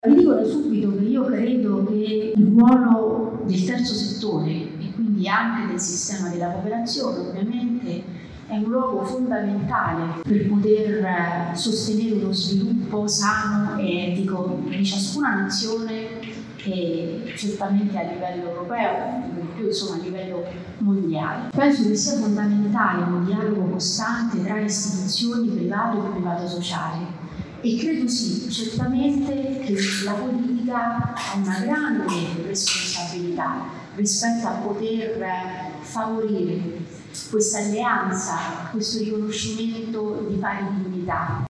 Sul ruolo della cooperazione sociale e del terzo settore in generale è intervenuta la viceministra al Lavoro e alla Politiche sociali Maria Teresa Bellucci che ha sottolineato proprio il valore del non profit per lo sviluppo del Paese.